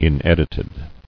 [in·ed·it·ed]